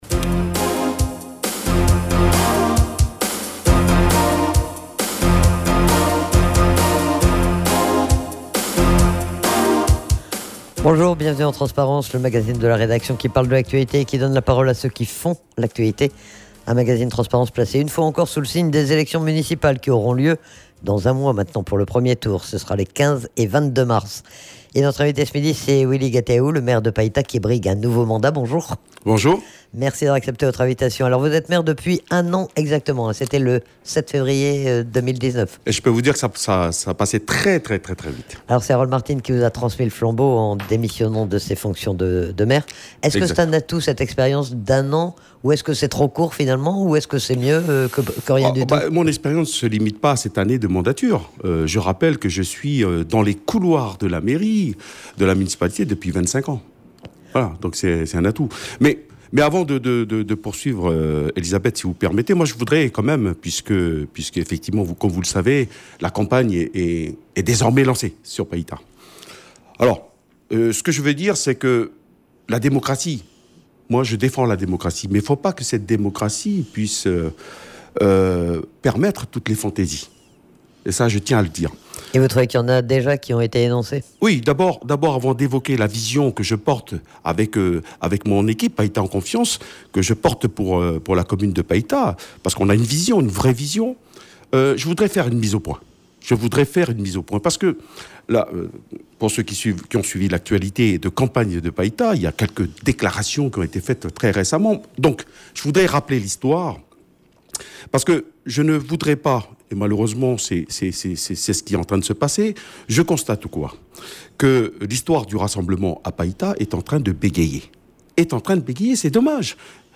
Elu maire il y a un an, pour succéder à Harold Martin, Willy Gatuhau est candidat à un nouveau mandat. Il est interrogé sur ses motivations, sur son bilan, sur son programme mais aussi sur l'équipe qu'il a constituée en vue scrutin et sur ses adversaires dans cette campagne.